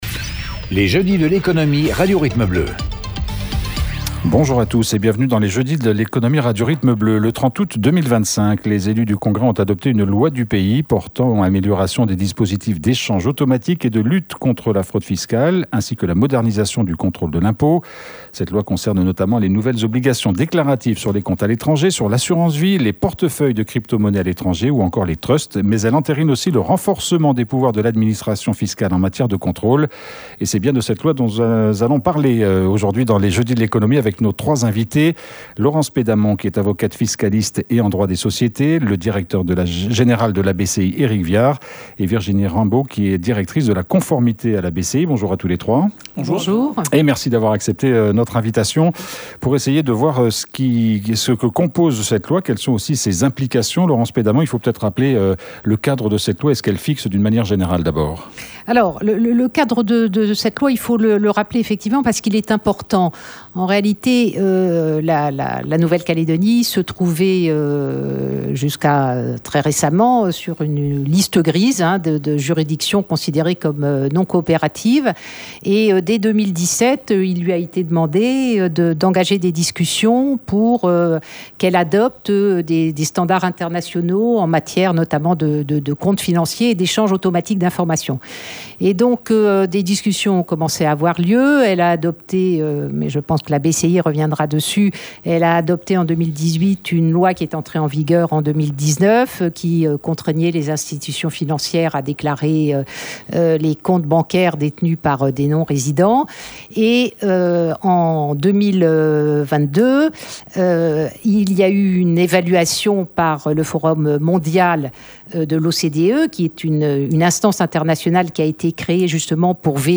avocate fiscaliste et en droit des sociétés